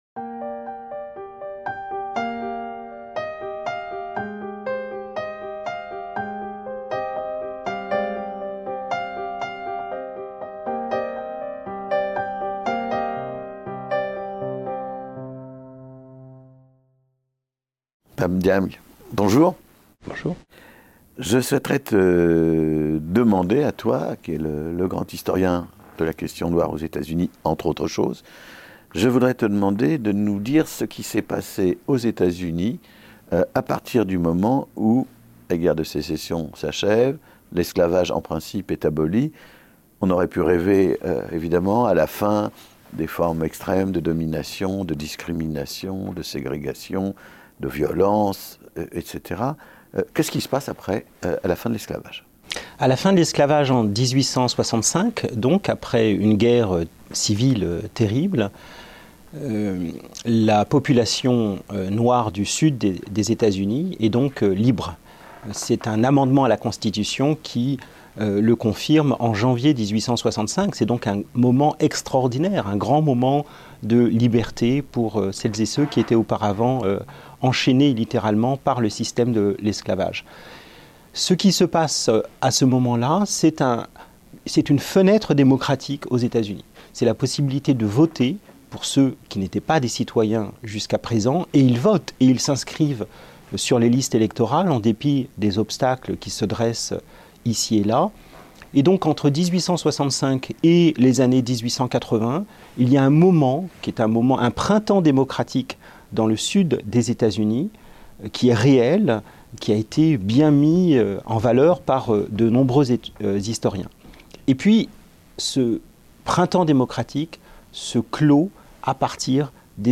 Ségrégation aux Etats-unis - Un entretien avec Pap Ndiaye | Canal U